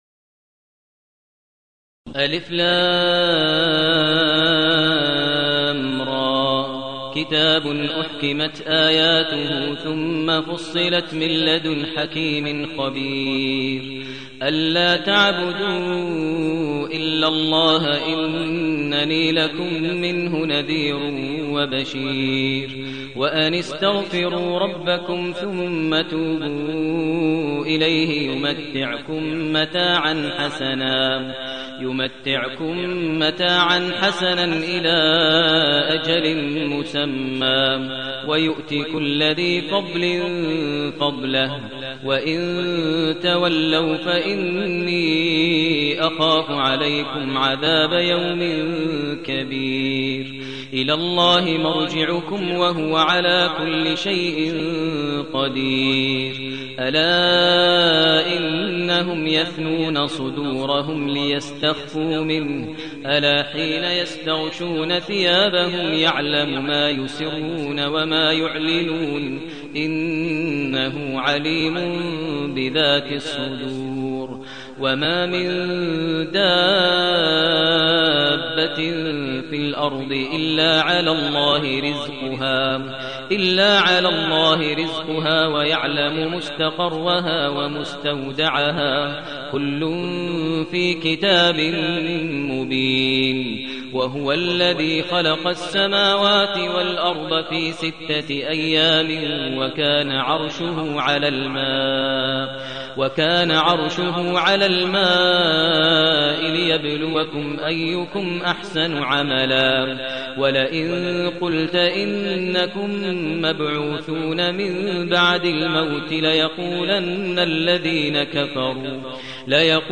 المكان: المسجد الحرام الشيخ: فضيلة الشيخ ماهر المعيقلي فضيلة الشيخ ماهر المعيقلي هود